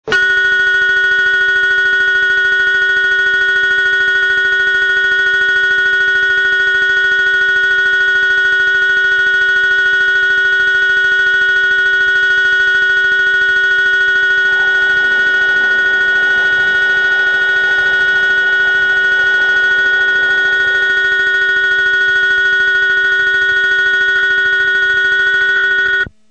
◆ 2001年3月頃収録したもの　---テレコ収録につき音量注意---
上下線共にカラカラベルのみ。　（機器同様により、けやき台にて収録したものを掲載）
接近ベル (58KB/11秒) ベル CMT
それ以前は九州らしいカラカラベルのみ鳴っていました。